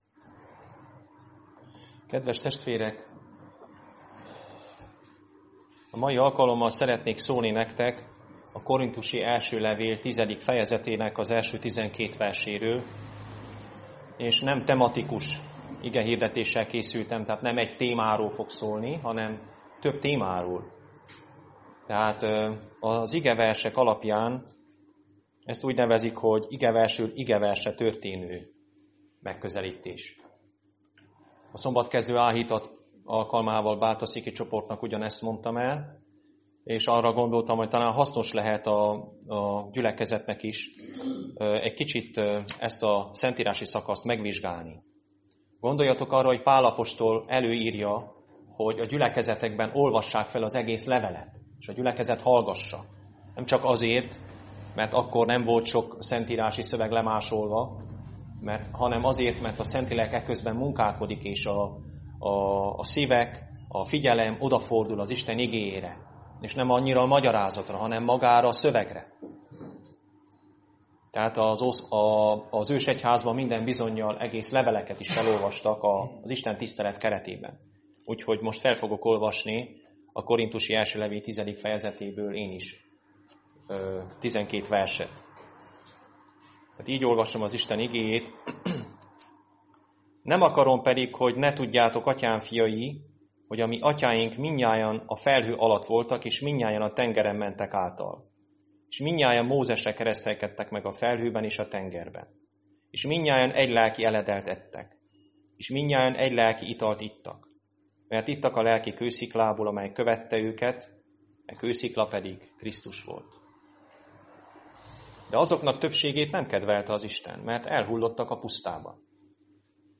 Igehirdetések mp3 Link az igehirdetéshez Hasonló bejegyzések Igehirdetések mp3 Ébredj fel a lelki halálból és felragyog neked...